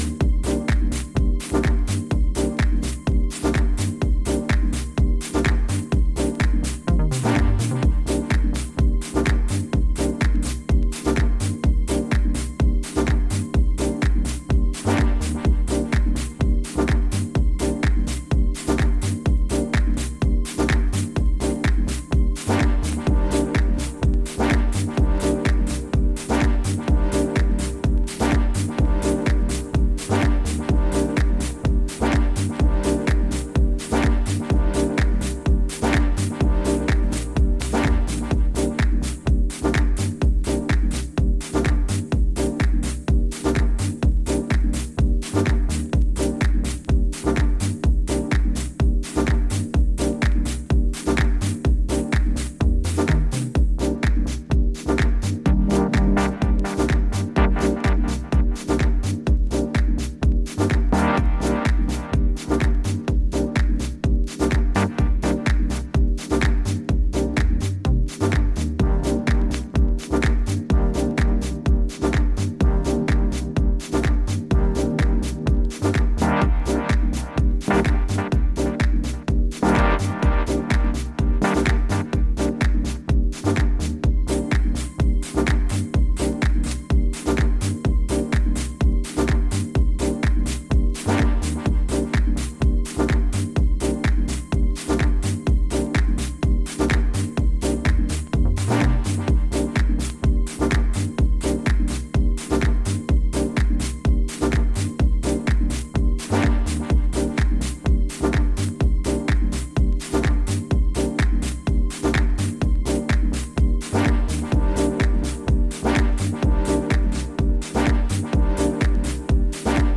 Afro Latin and deep house
seriously catchy vibes